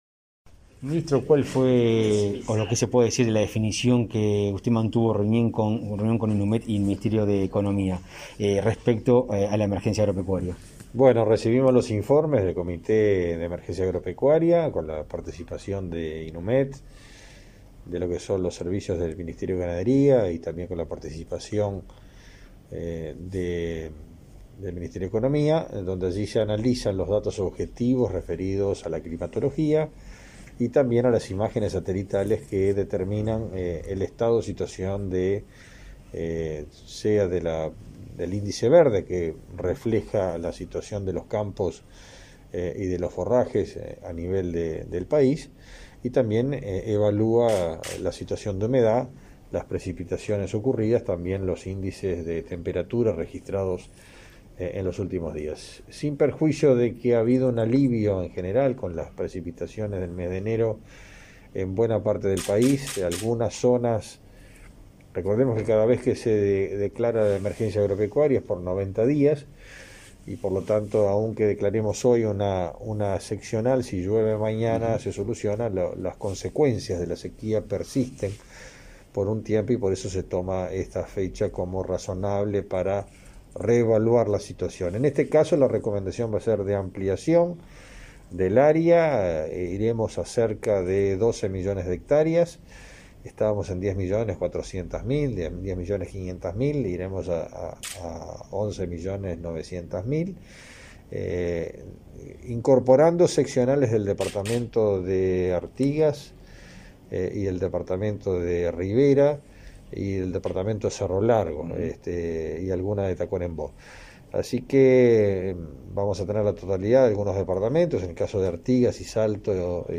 Entrevista al ministro del MGAP, Fernando Mattos
Mattos explicó a Comunicación Presidencial los alcances de la medida.